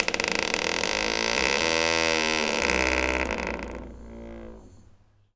door_creak_long_01.wav